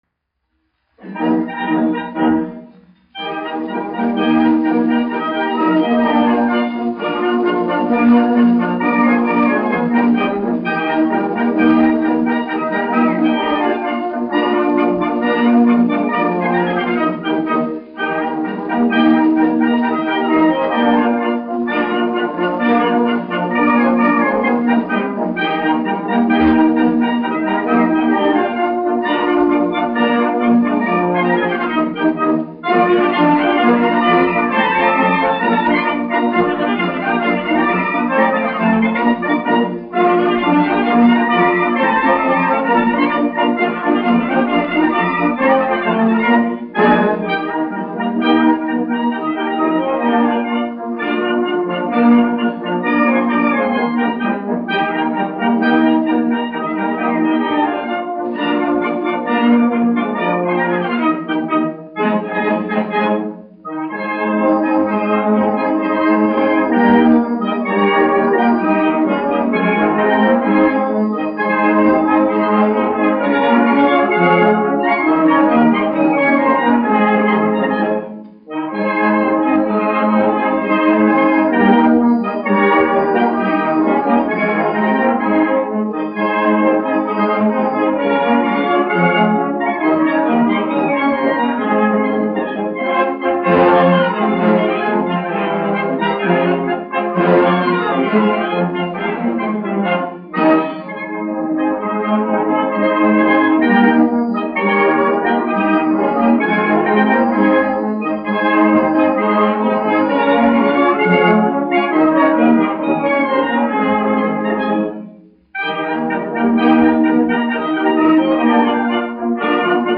1 skpl. : analogs, 78 apgr/min, mono ; 25 cm
Polkas
Pūtēju orķestra mūzika
Skaņuplate